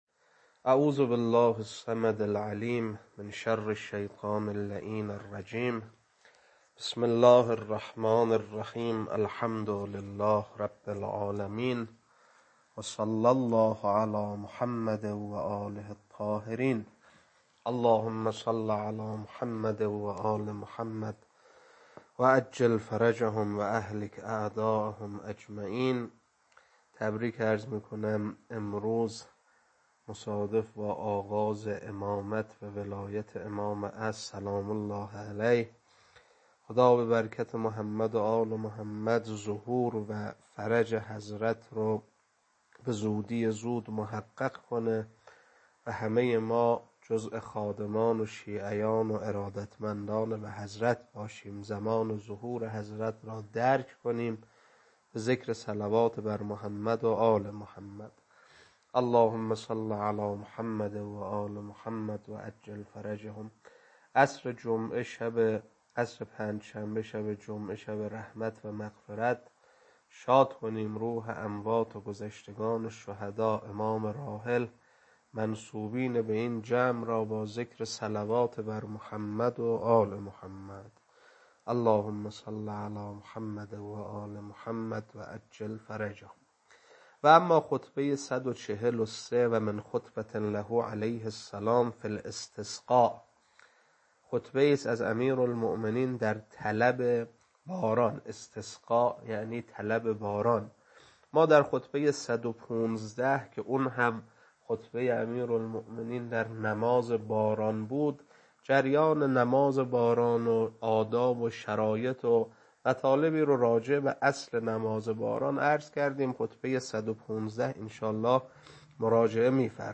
خطبه-143.mp3